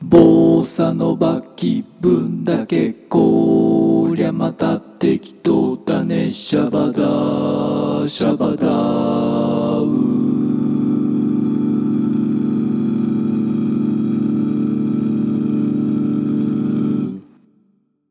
で、正しい音にどれぐらいのスピード、アタック、リリースで補正するか、というパラメータを指定すれば、元音程（赤色）が正しい音程（緑色）にリアルタイムで補正されます。
よし、ボサノバごっこだ。
短い音や外れすぎている音はちょっと厳しいですが、白玉にはよく効いていると思います。それにしてもひどい音痴だなあ。